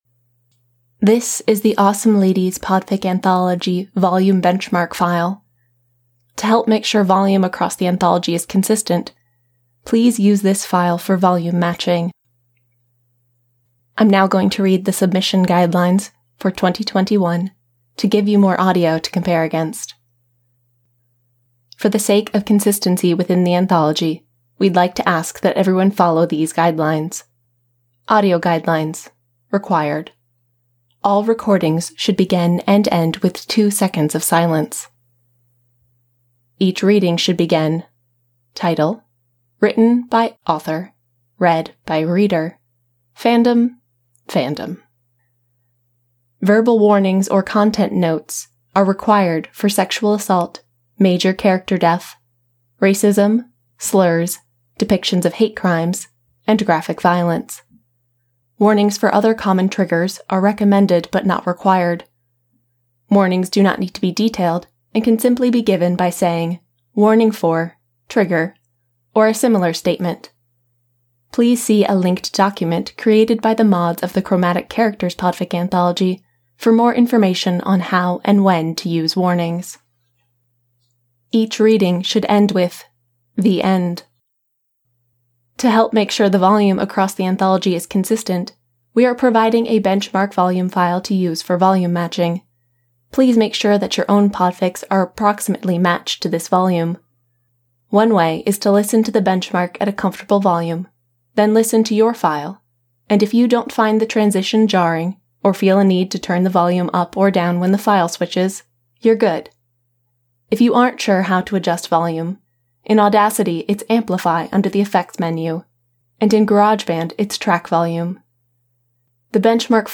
The benchmark file has been set to the Auphonic defaults, so another method is to use Auphonic to adjust your podfics to match.